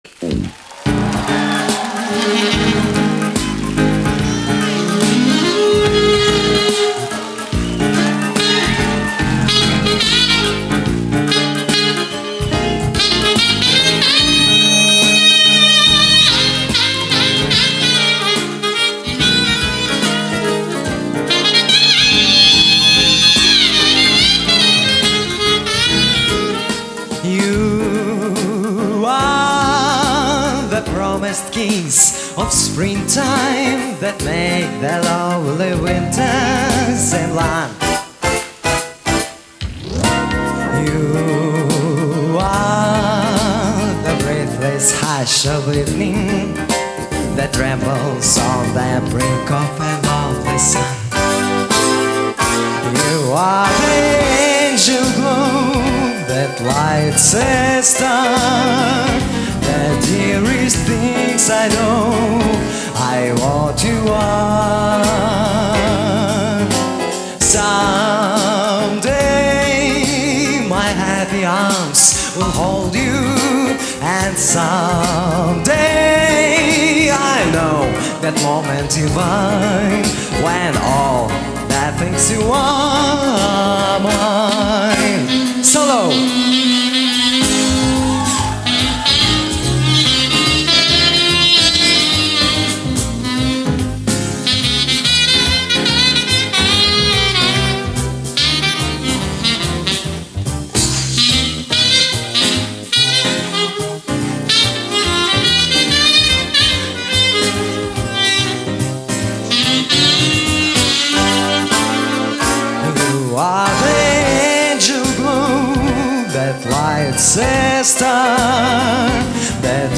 Запись была сделана мной с Тюнера AverMedia TV-Phon